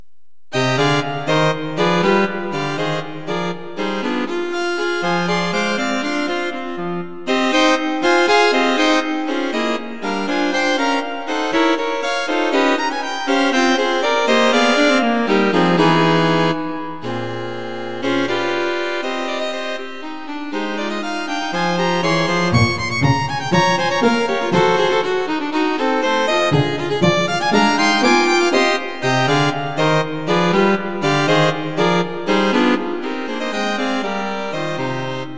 String Quartet for Concert performance
A modern, spiky piece for String Quartet